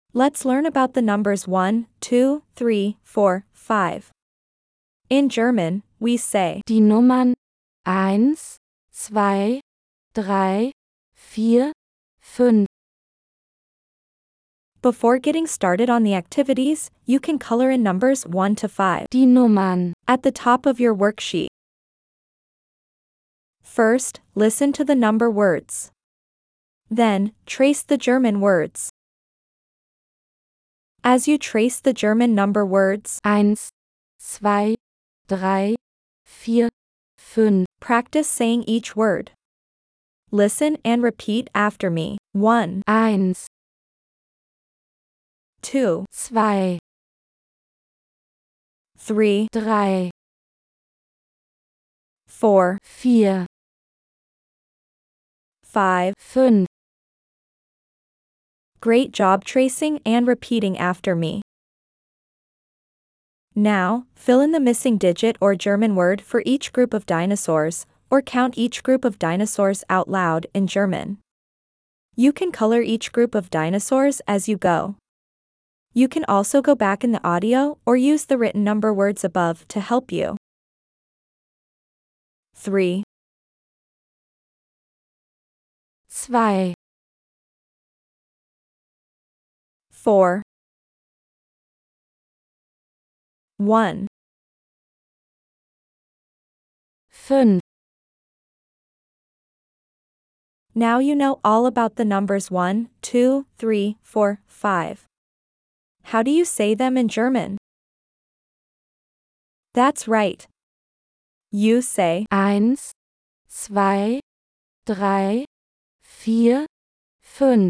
If you haven’t received your first box yet, you’ll find a free introduction to number words and an accompanying audio file for pronunciation at the end of this post.